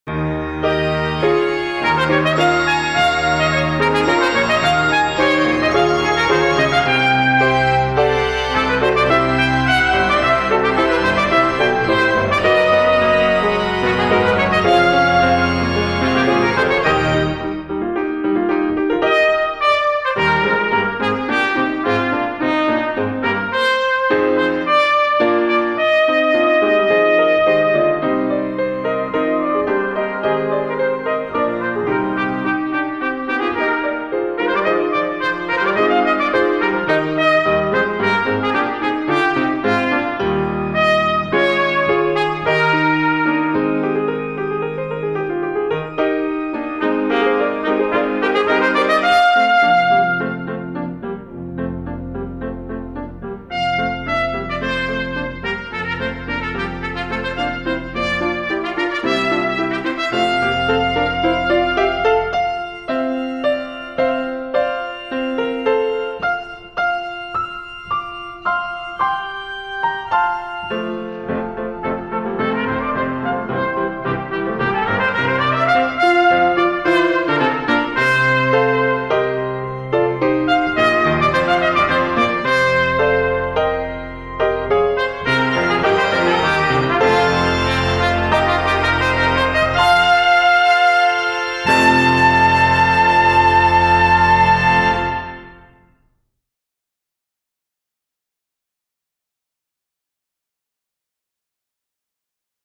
Instrumental Solos Horn in F
F Horn
Full-performance